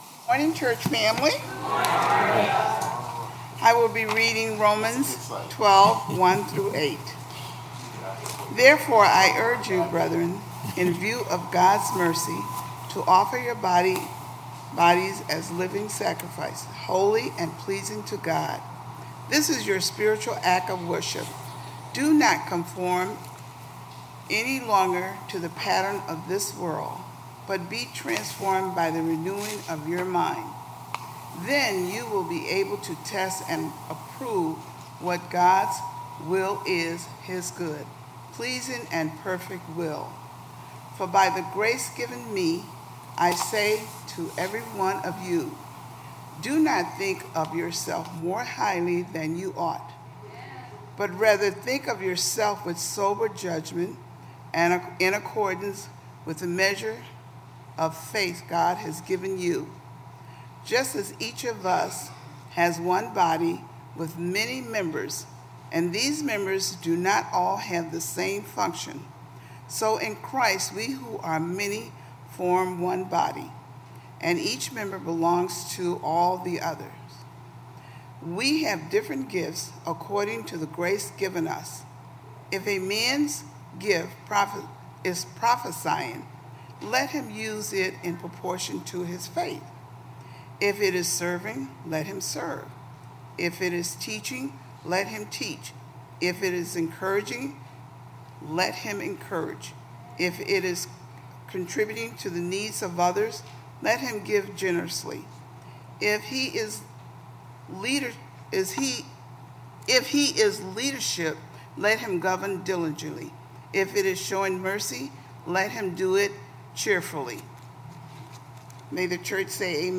Worship Service 8/6/17